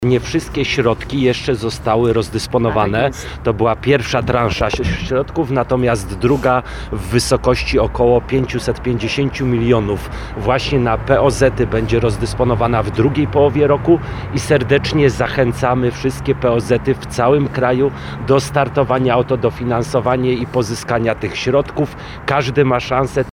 Informację o pieniądzach dla POZ-ów przekazała podczas konferencji prasowej zorganizowanej przed przychodnią Troclik na os. Karpackim w Bielsku-Białej.